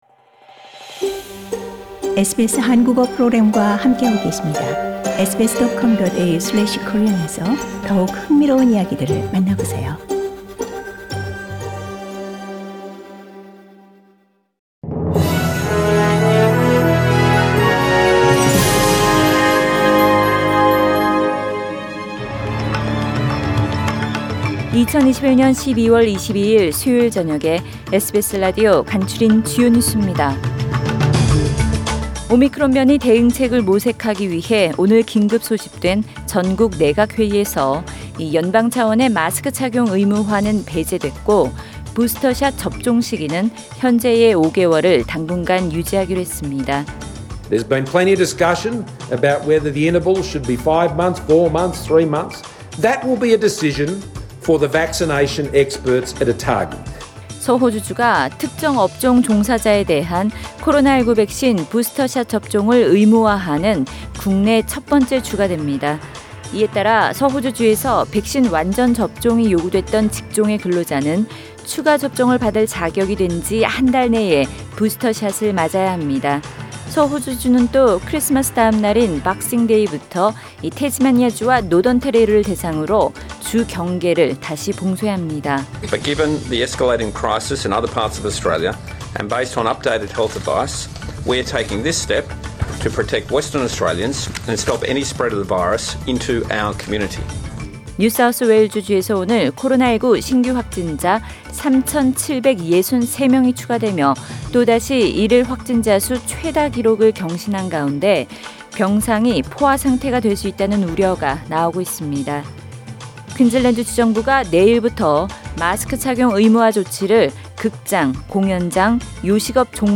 SBS News Outlines…2021년 12월 22일 저녁 주요 뉴스
2021년 12월 22일 수요일 저녁의 SBS 뉴스 아우트라인입니다.